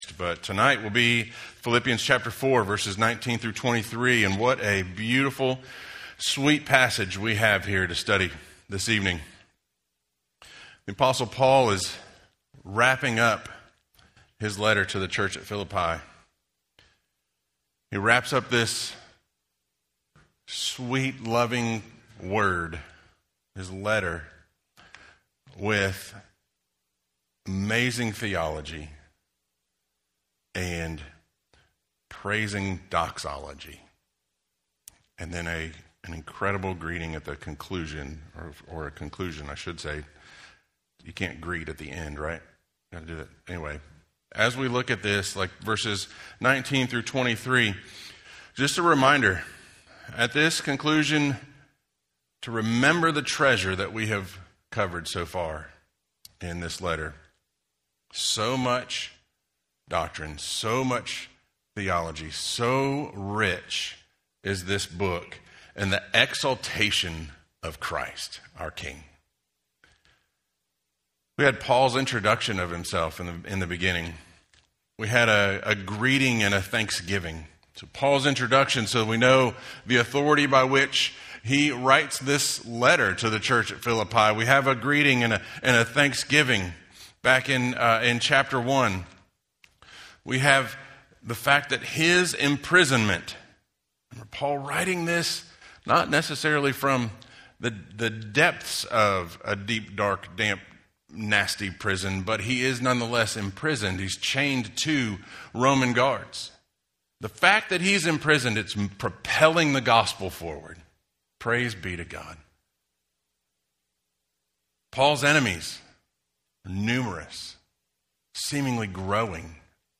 Home › Sermons › Four Lessons From Philippi